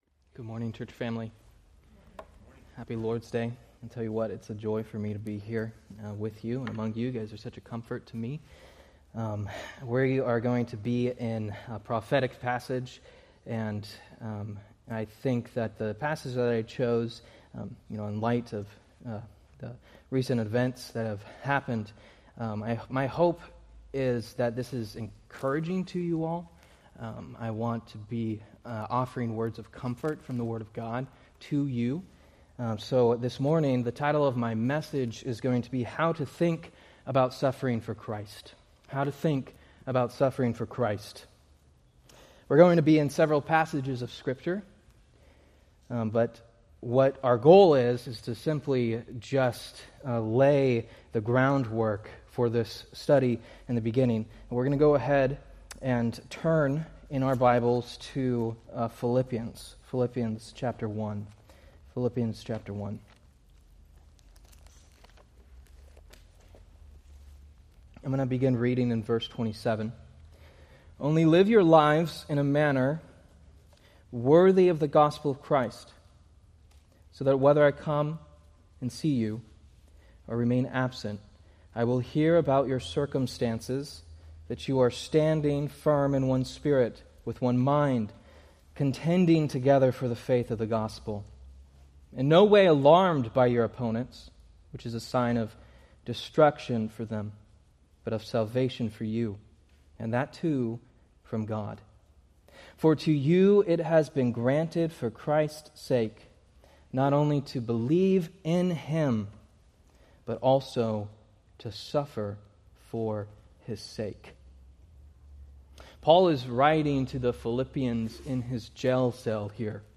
Date: Sep 14, 2025 Series: Various Sunday School Grouping: Sunday School (Adult) More: Download MP3 | YouTube